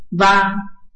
臺灣客語拼音學習網-客語聽讀拼-海陸腔-單韻母
拼音查詢：【海陸腔】va ~請點選不同聲調拼音聽聽看!(例字漢字部分屬參考性質)